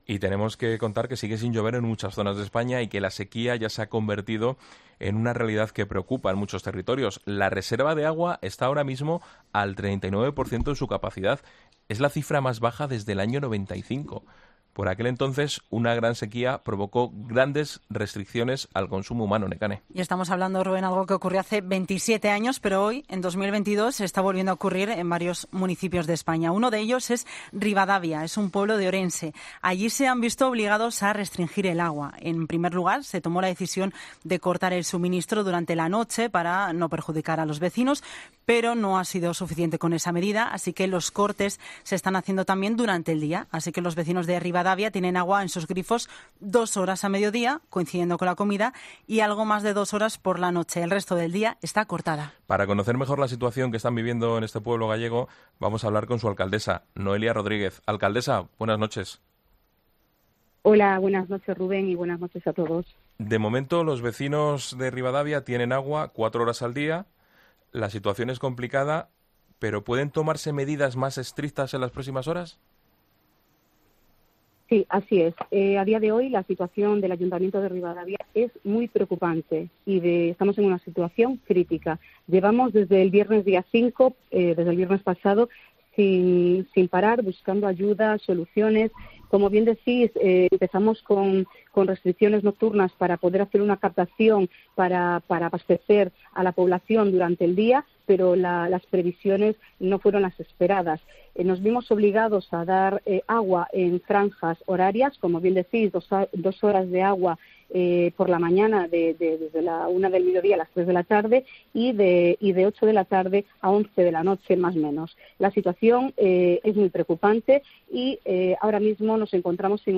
La alcaldesa del municipio ha explicado en 'La Linterna' cuáles son las medidas restrictivas que se han visto obligados a aplicar
Para conocer mejor la situación vamos a hablar con su alcaldesa, Noelia Rodríguez.